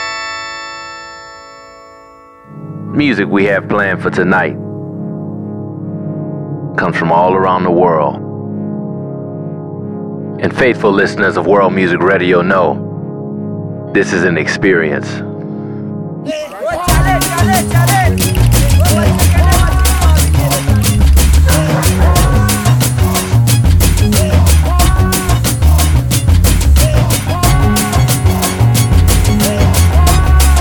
R&B Soul